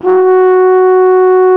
TENORHRN F#2.wav